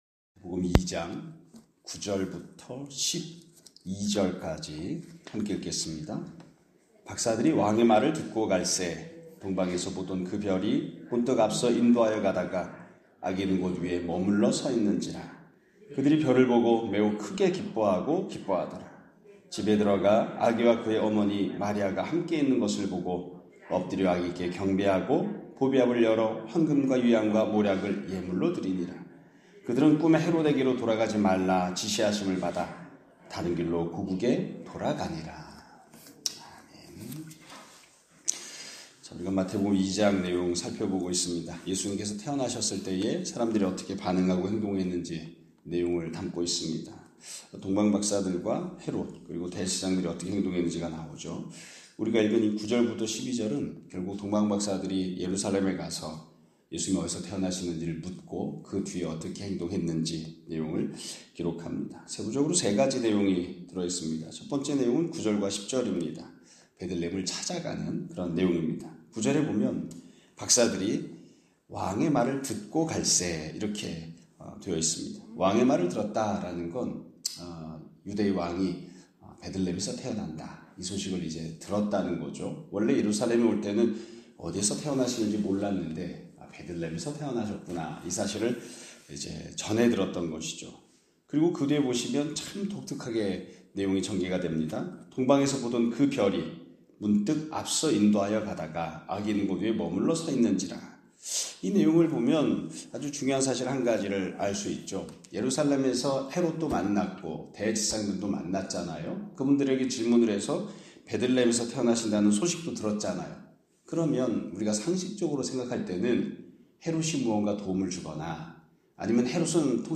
2025년 4월 2일(수 요일) <아침예배> 설교입니다.